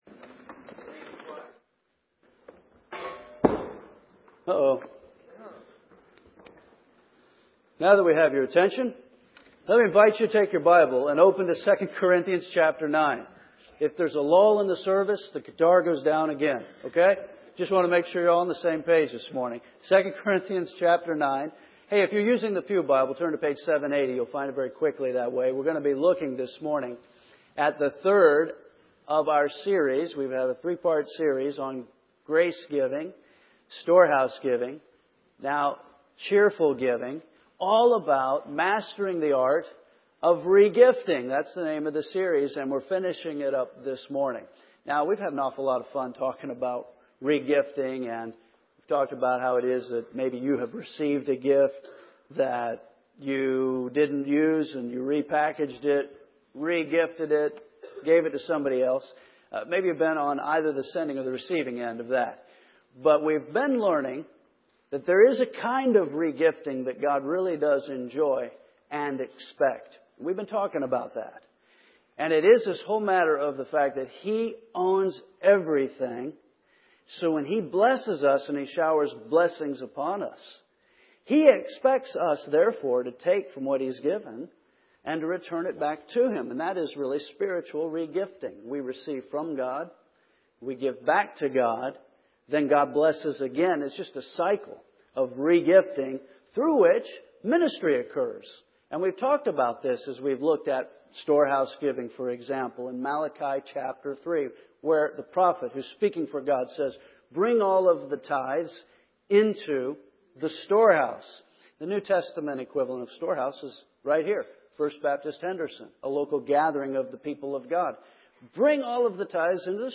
First Baptist Church Henderson KY